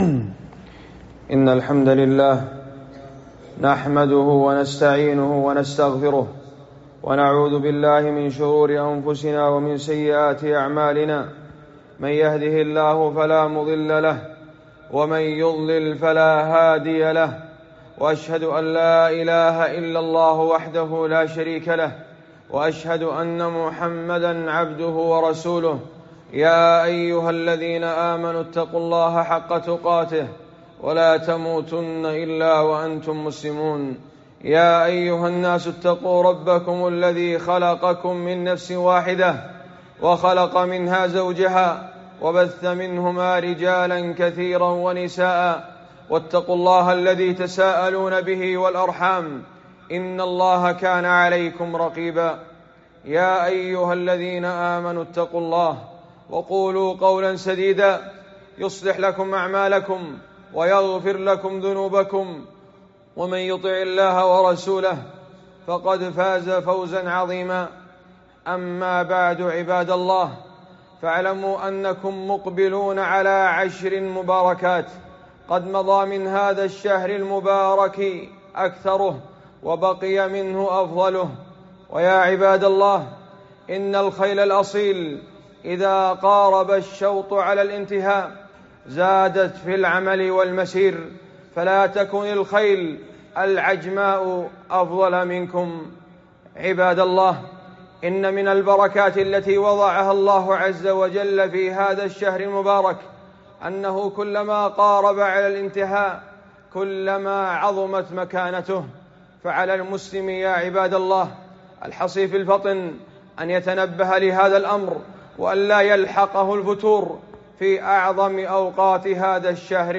خطبة - الحث على استغلال العشر والحذر من الرؤى والمنامات